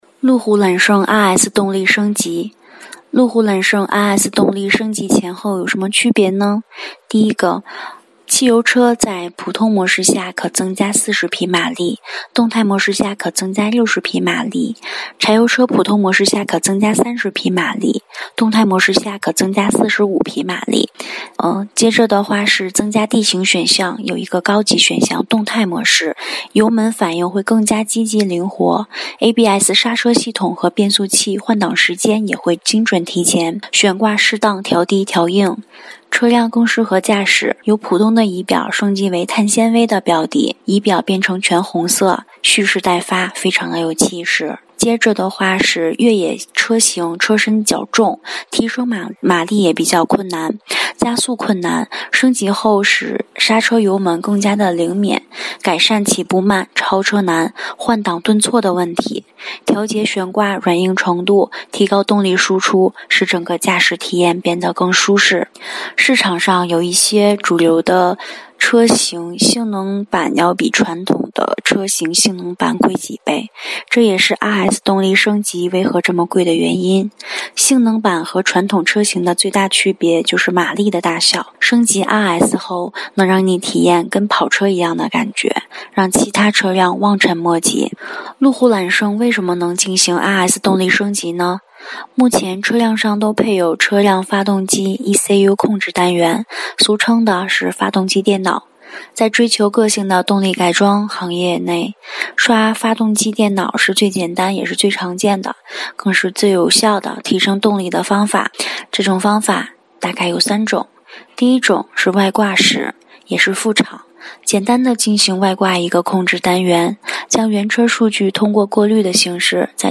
这个音频挺好听，一定是个美女读的。